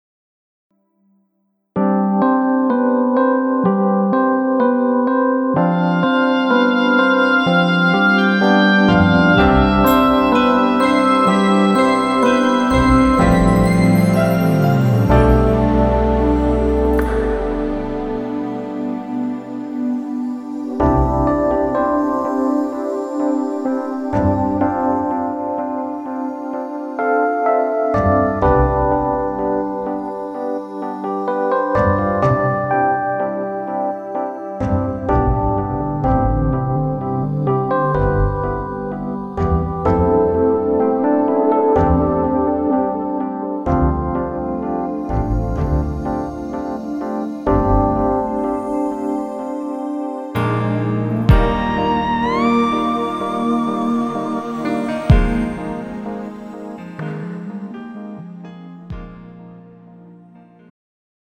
장르 축가 구분 Pro MR
가사   (1절 앞소절 -중간삭제- 2절 후렴연결 편집)